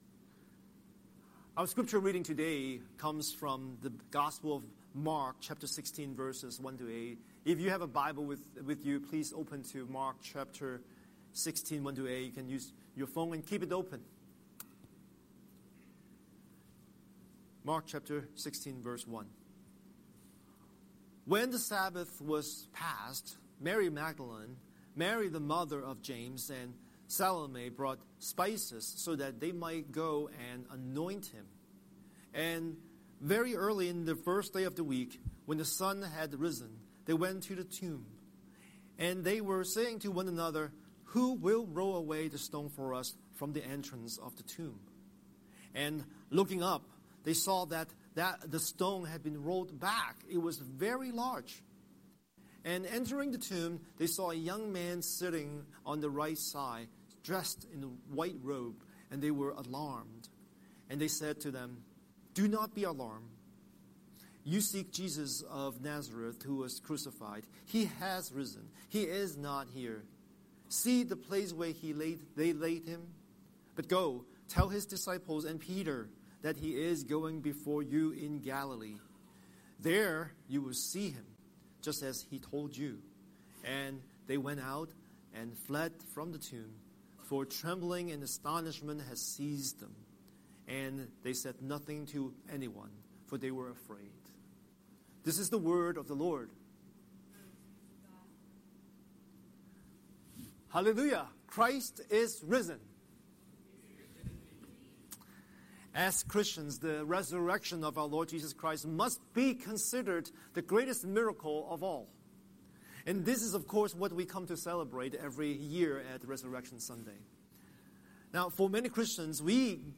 Scripture: Mark 16:1–8 Series: Sunday Sermon